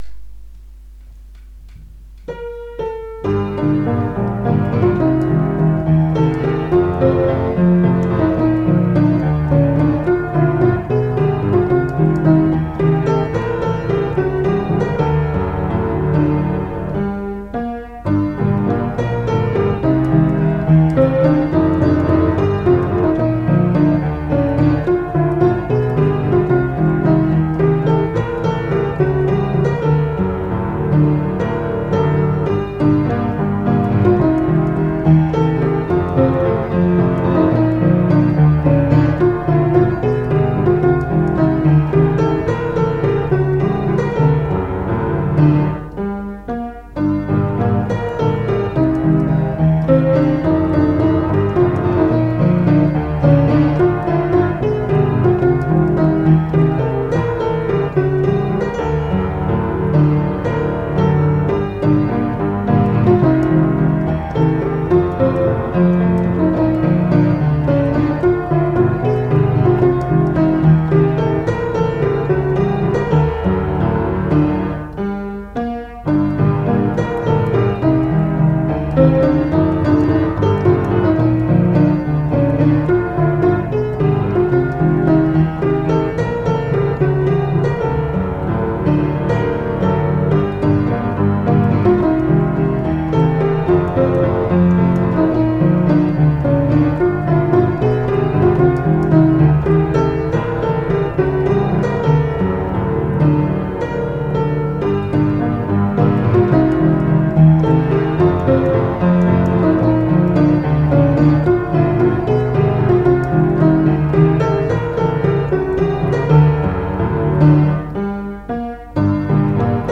А на сайт пришлось занести мелодии в исполнении, со словами.